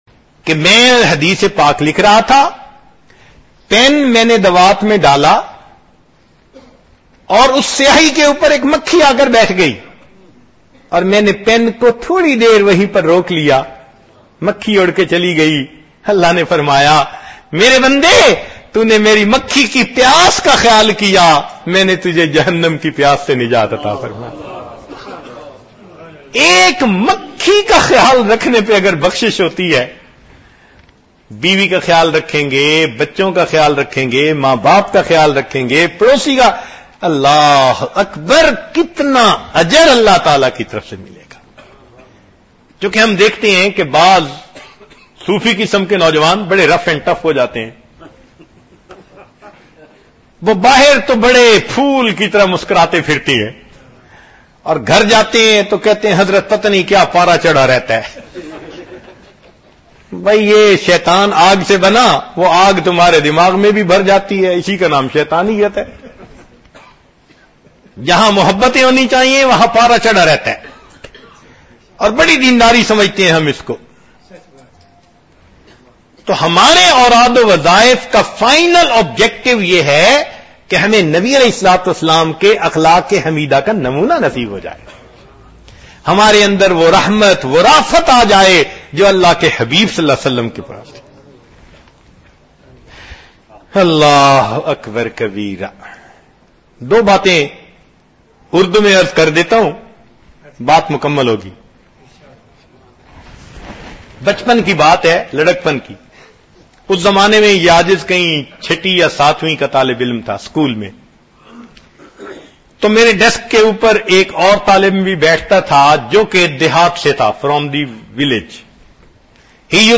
ENGLISH LECTURE - Venue: MUSJID-E-NOOR -
MALLINSON RD, DURBAN, SOUTH AFRICA  09 May 2011